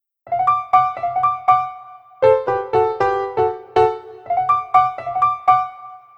piano.wav